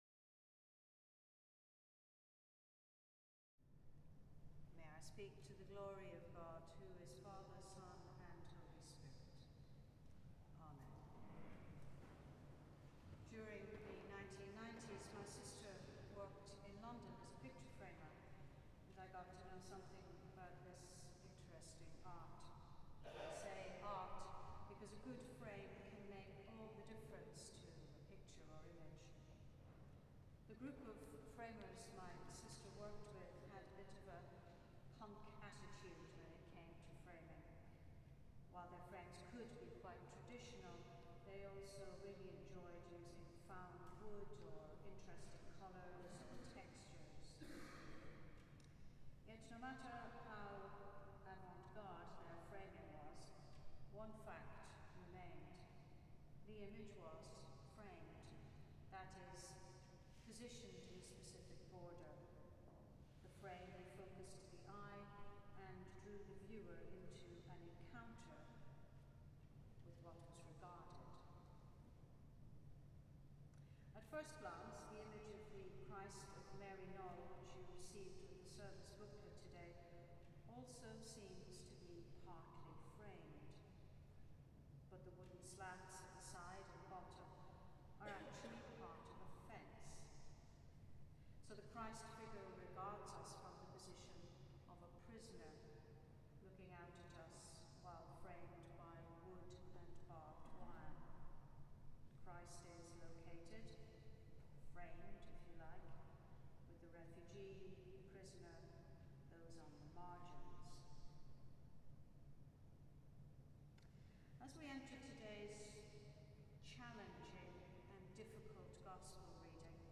Sunday Eucharist Sermons MT18 | St John's College, University of Cambridge